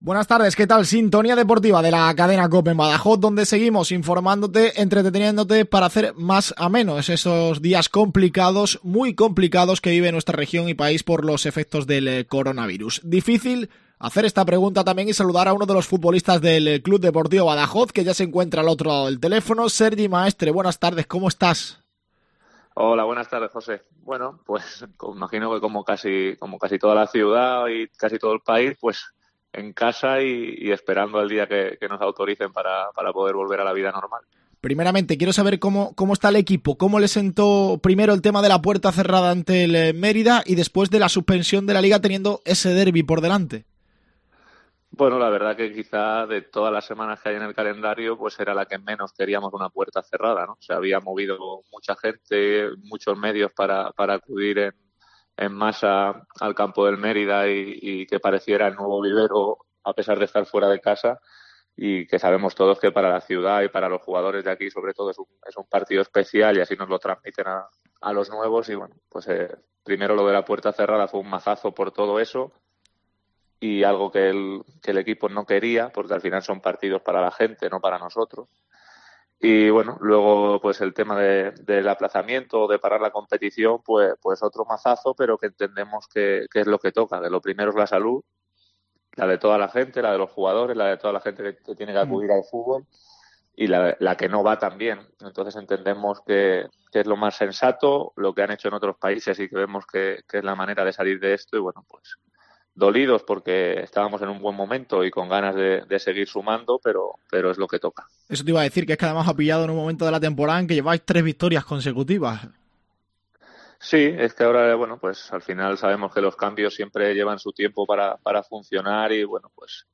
Entrevista
pasó por los micrófonos de COPE para analizar la situación en la que se encuentra el deporte en nuestro país y como afecta a los futbolistas